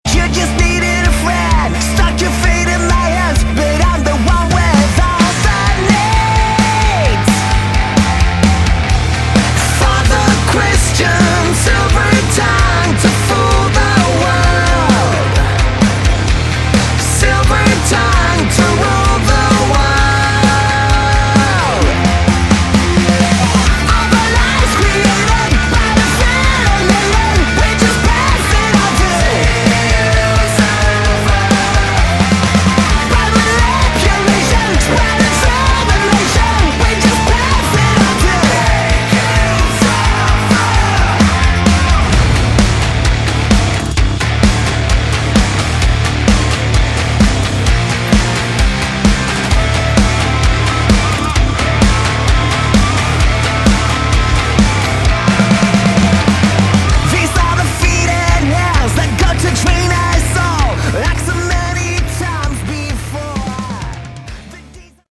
Category: Melodic Metal
Guitars, Vocals
Bass
Drums